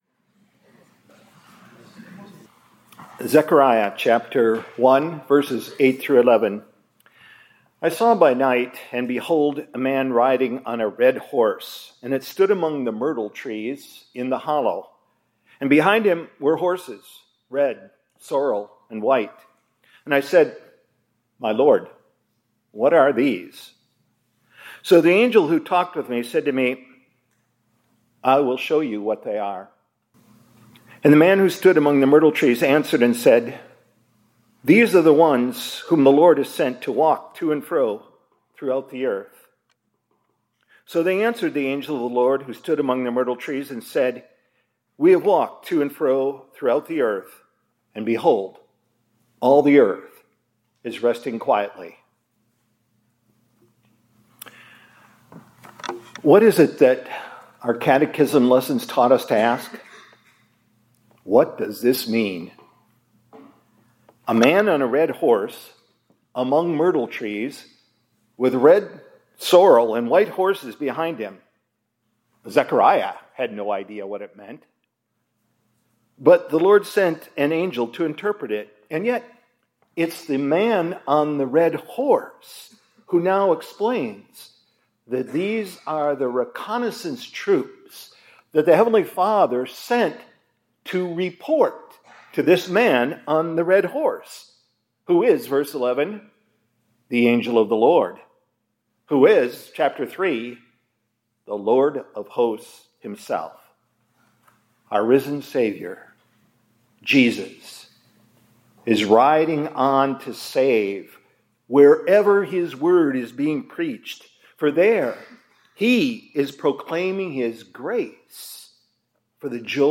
2026-04-27 ILC Chapel — The Angel of the Lord is establishing His kingdom hidden among the myrtle trees of His Word and sacraments